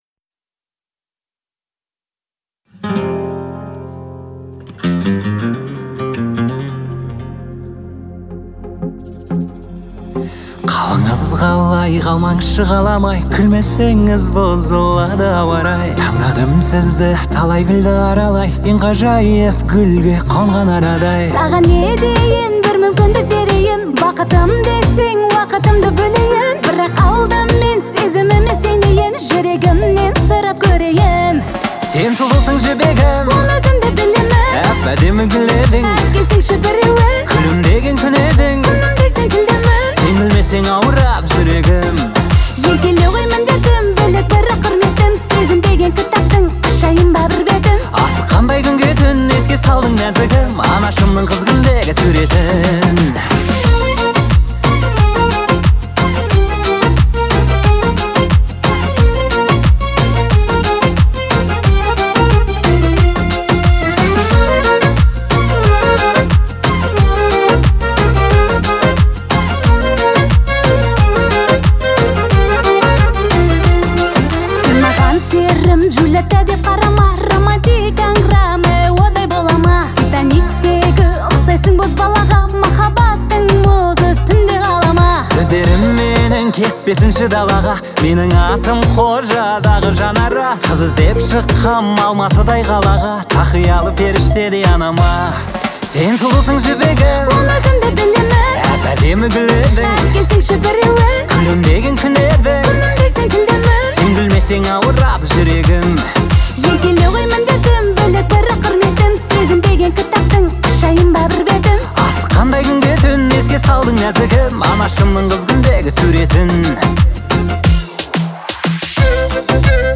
это нежный и романтичный трек в жанре поп.